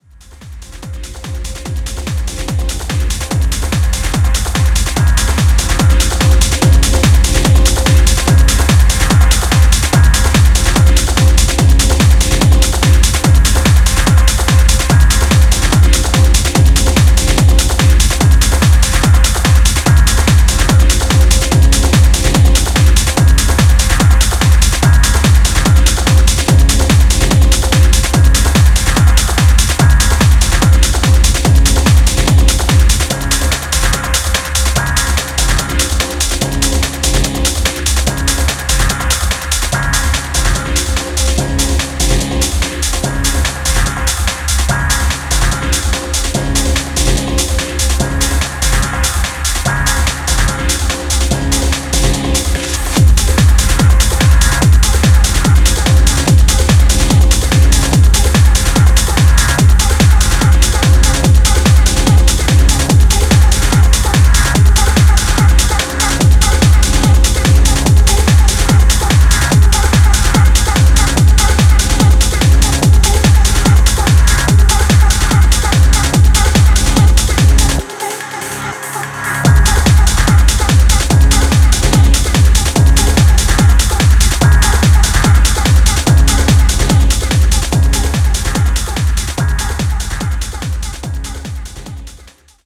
Dub Techno , Techno , Trance